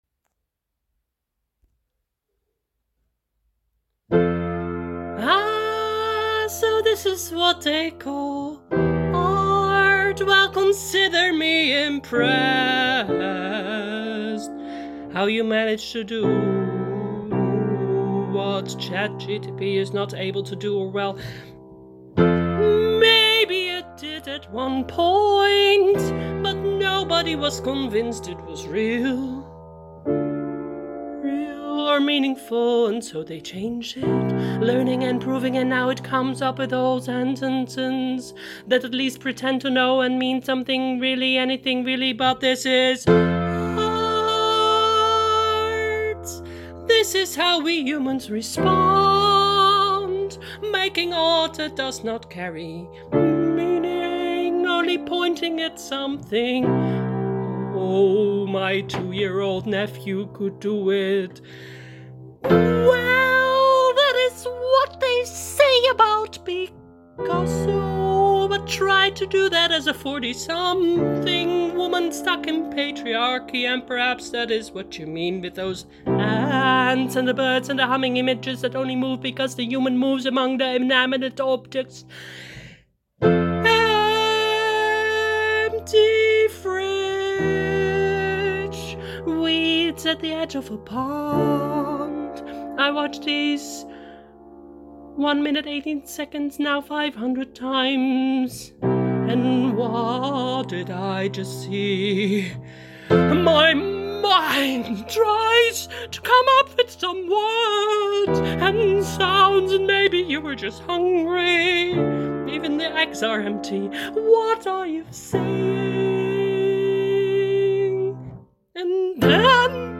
en Musical
en Song
en 8. Sound/music